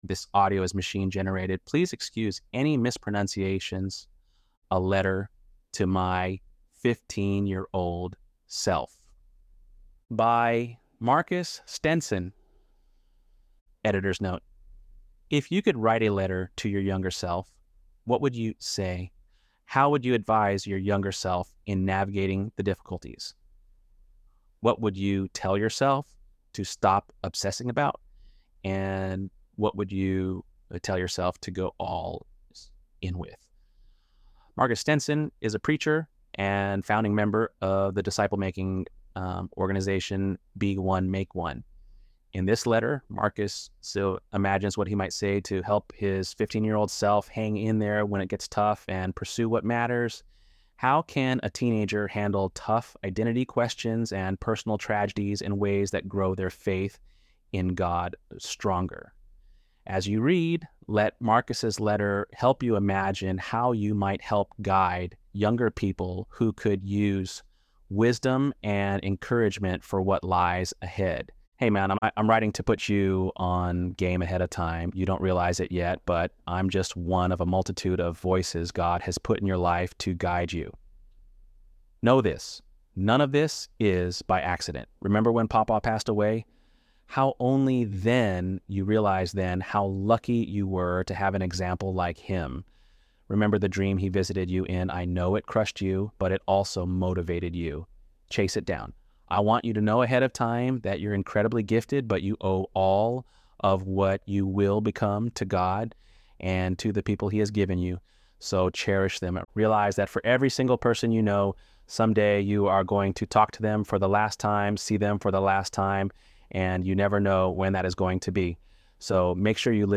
ElevenLabs_7.10_15_yo.mp3